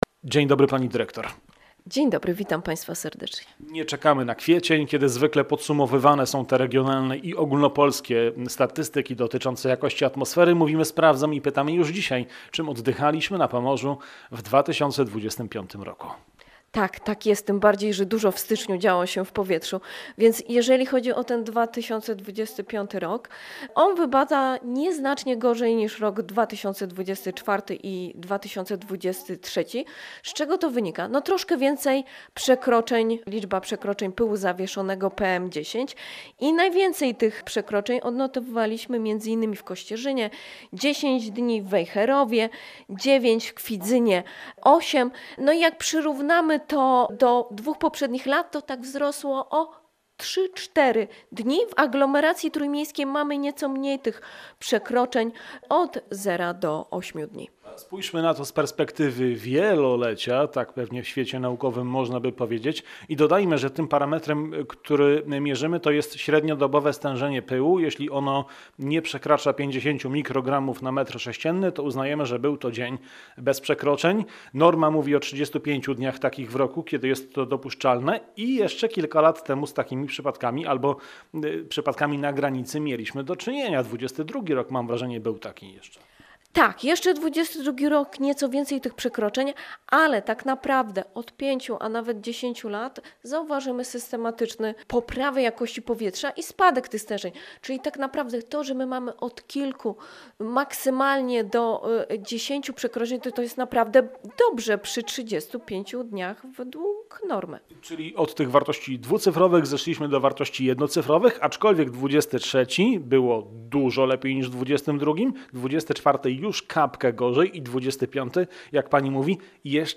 Ekspertka omawia najnowsze dane